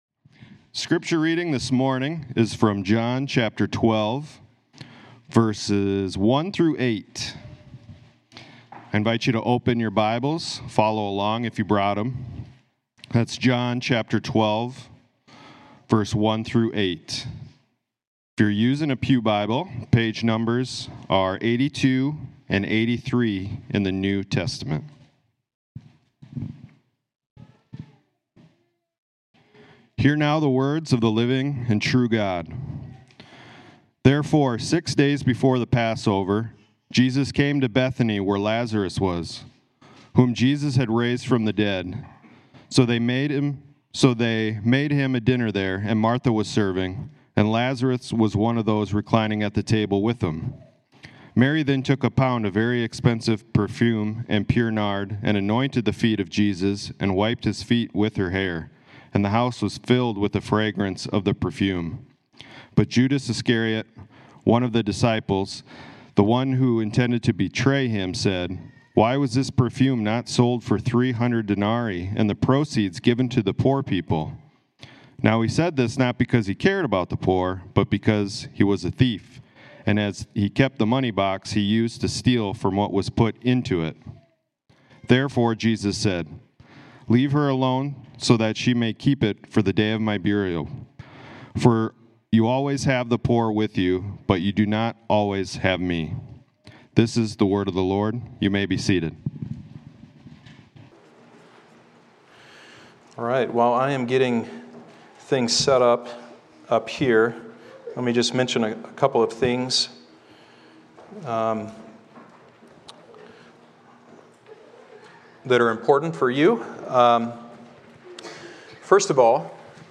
Sermons Podcast - You Do Not Always Have Me | Free Listening on Podbean App